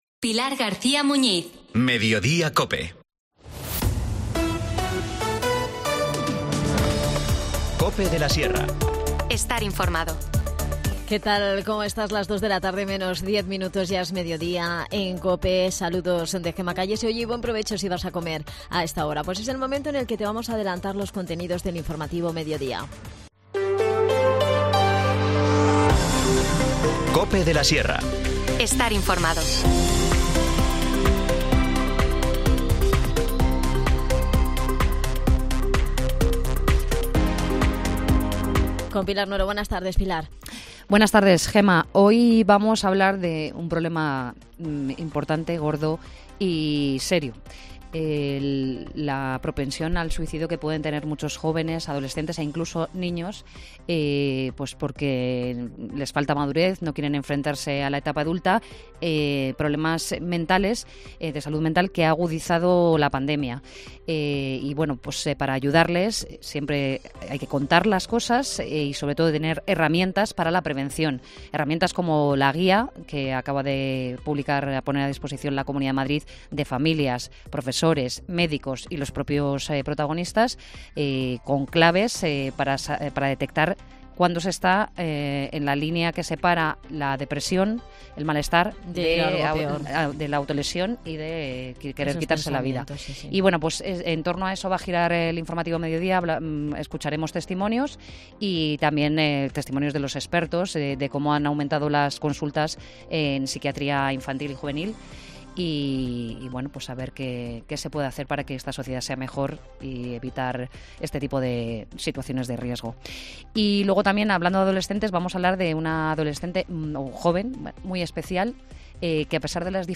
educador ambiental.